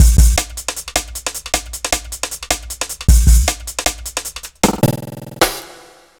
Index of /90_sSampleCDs/Zero-G - Total Drum Bass/Drumloops - 1/track 03 (155bpm)